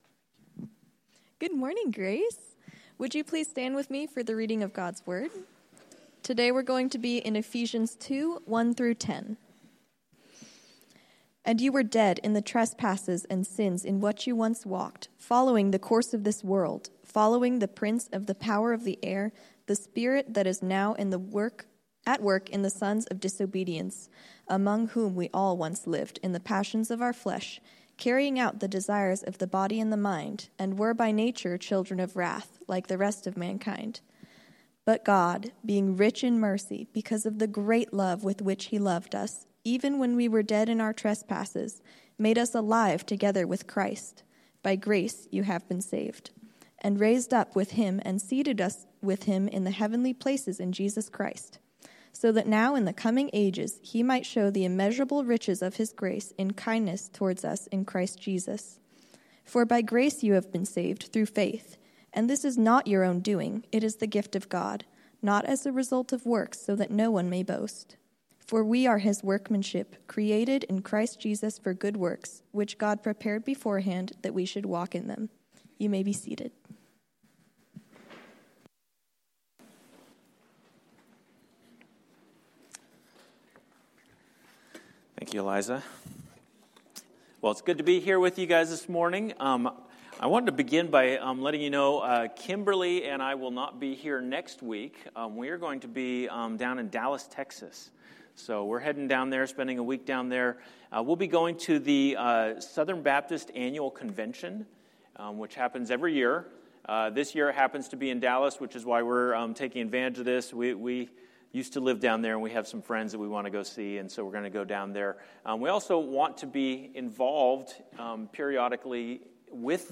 A message from the series "Discipleship Essentials."